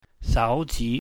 /sau/ /ji/